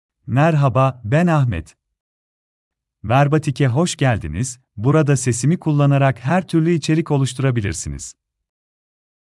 Ahmet — Male Turkish AI voice
Ahmet is a male AI voice for Turkish (Türkiye).
Voice sample
Listen to Ahmet's male Turkish voice.
Ahmet delivers clear pronunciation with authentic Türkiye Turkish intonation, making your content sound professionally produced.